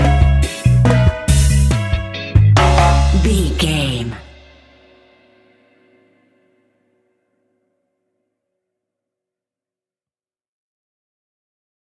Classic reggae music with that skank bounce reggae feeling.
Aeolian/Minor
C#
instrumentals
laid back
chilled
off beat
drums
skank guitar
hammond organ
transistor guitar
percussion
horns